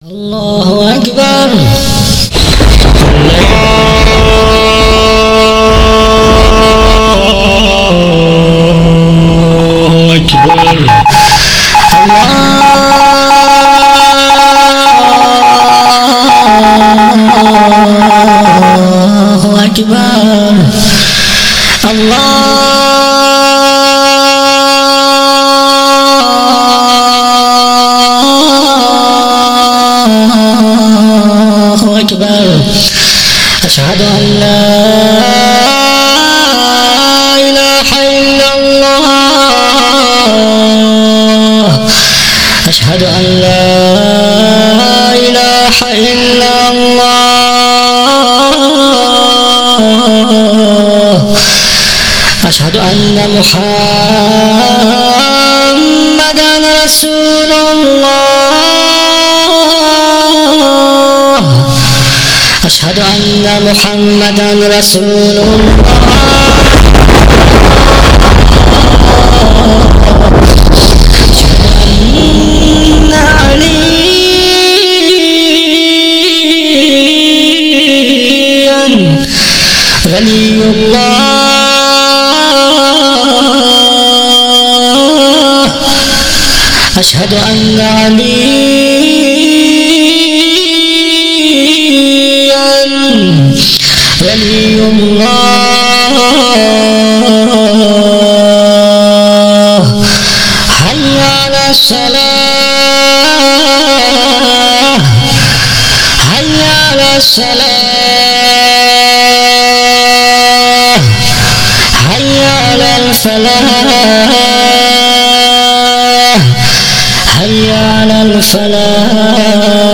اذان حرم ، مکه